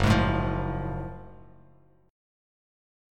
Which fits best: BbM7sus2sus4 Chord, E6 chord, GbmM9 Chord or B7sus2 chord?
GbmM9 Chord